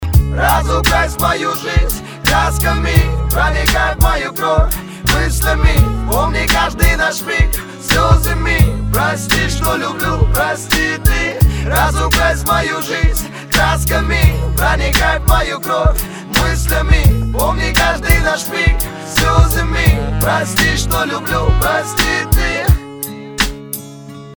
мужской голос
грустные
русский рэп
спокойные
Грустный русский рэп